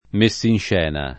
meSSinš$na] s. f. — raro il pl. messinscene, e più raro il pl. inv.: con le sfolgoranti messinscena delle loro vecchie e nuove meraviglie [